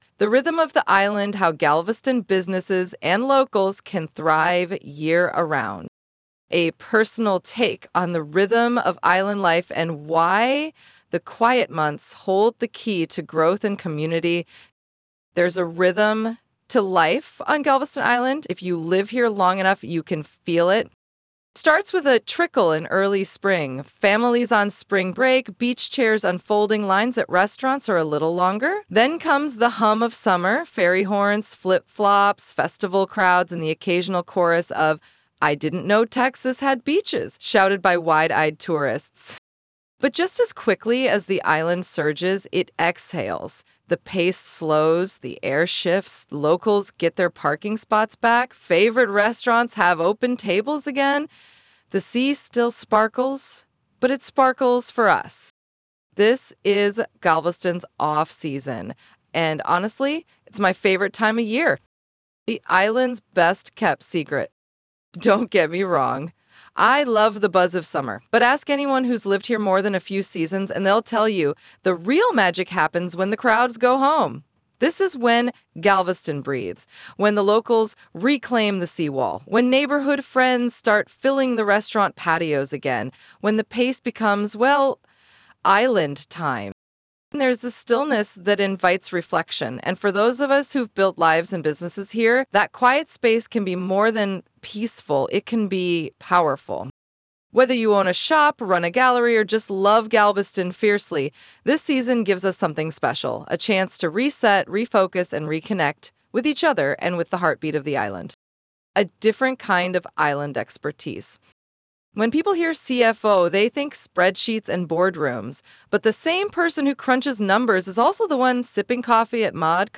Here’s an audio version of the story